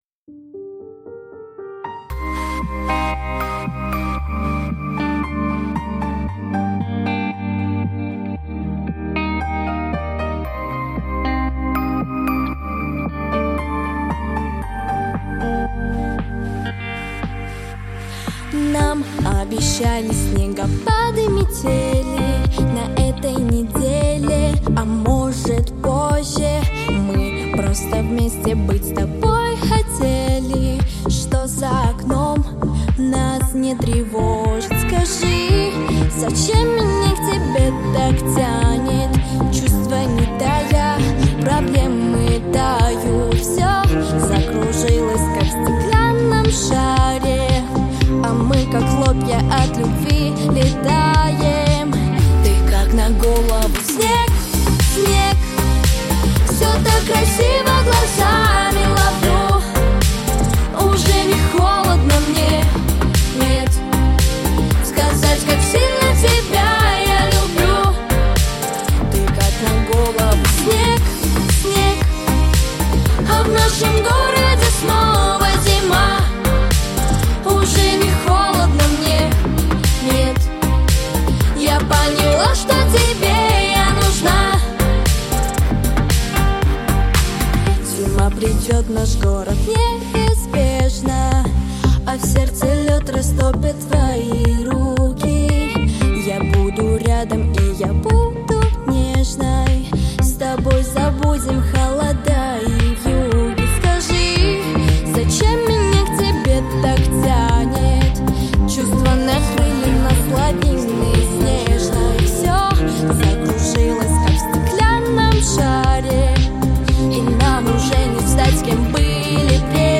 🎶 Детские песни / Песни про Зиму 🥶